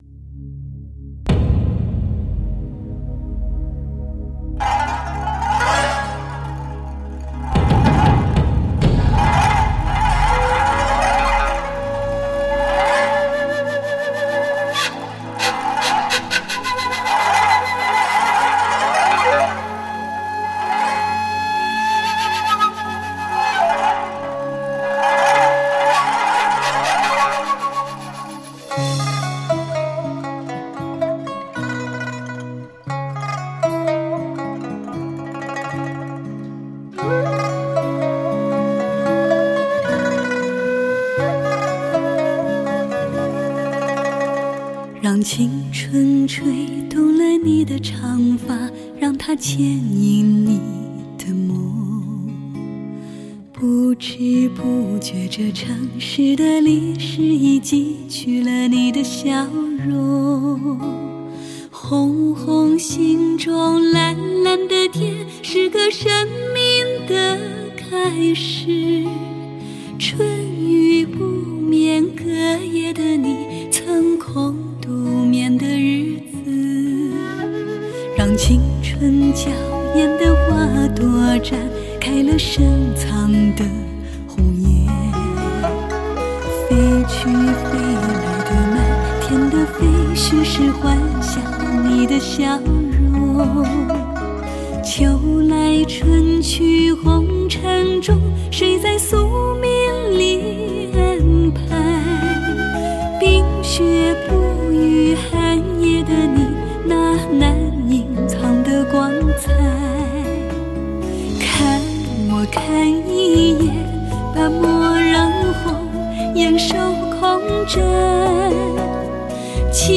经典老歌篇
立体环绕音效 德国母带后期制作